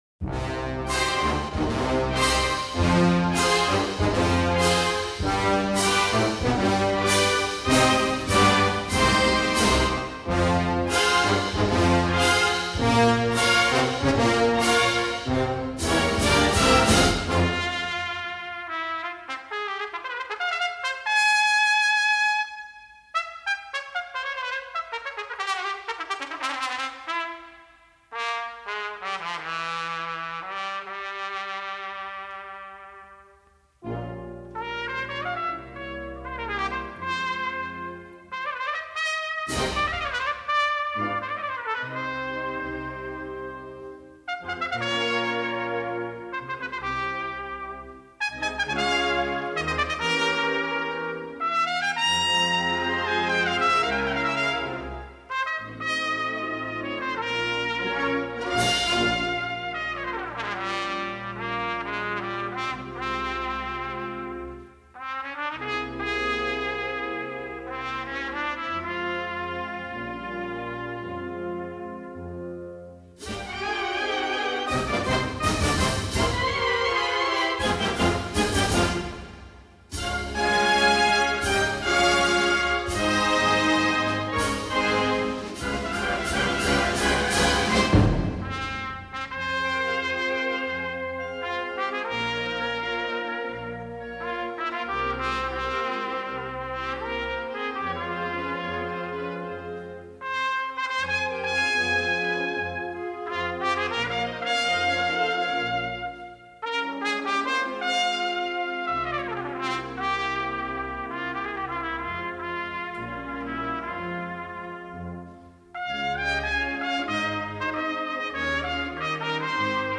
cornet